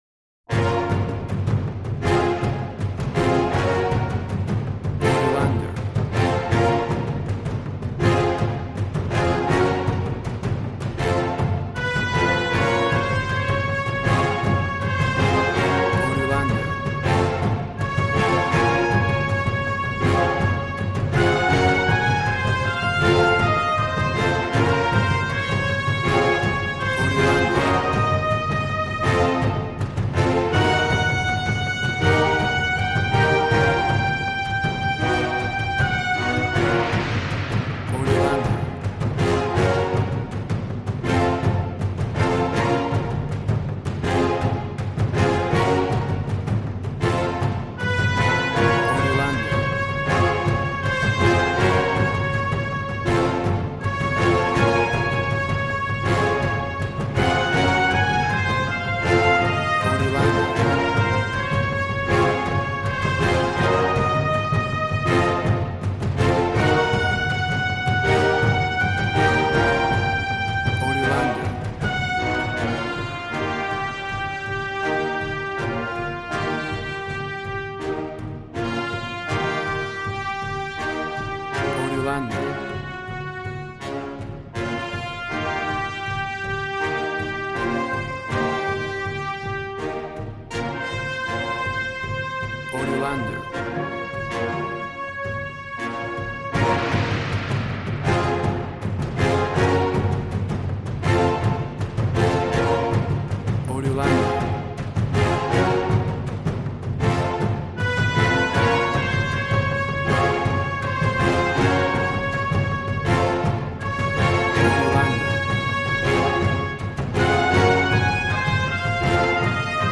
Regal and romantic, a classy piece of classical music.
Tempo (BPM): 81